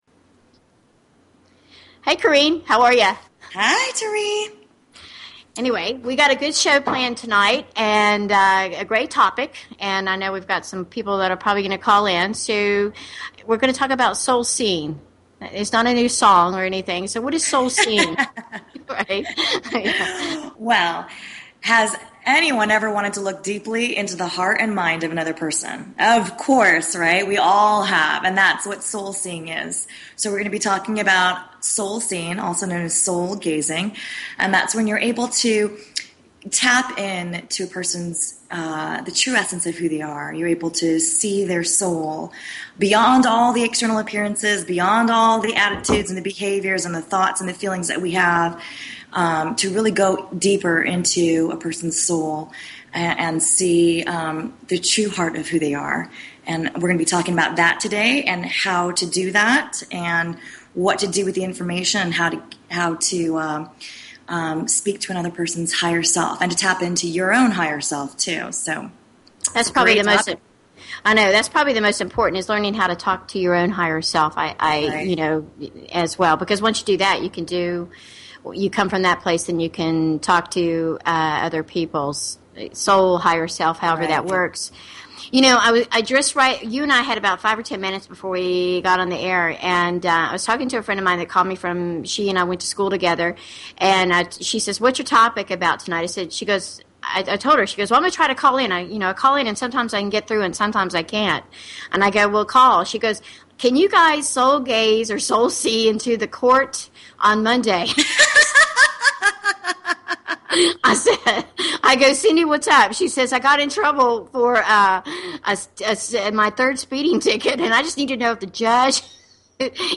Talk Show Episode, Audio Podcast, Angel_Coaches and Courtesy of BBS Radio on , show guests , about , categorized as